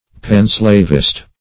Panslavist \Pan`slav"ist\, n.
panslavist.mp3